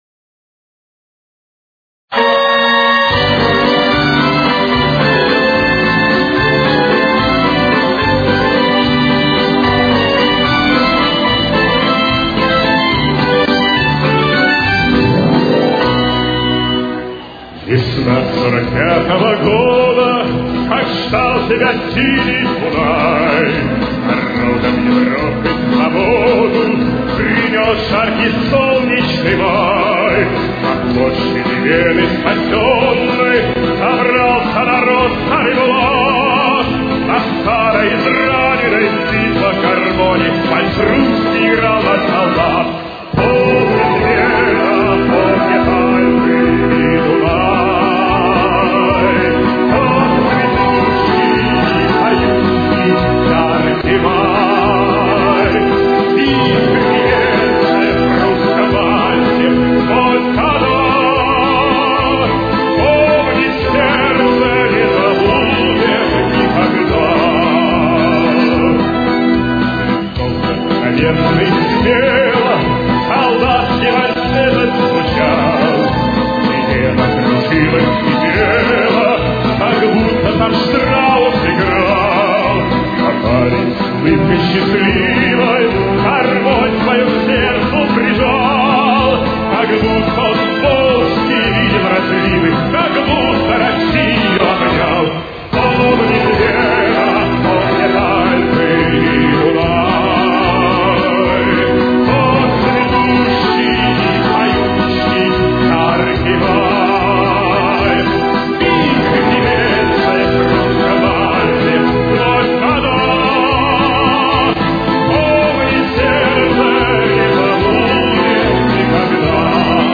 Ми минор. Темп: 224.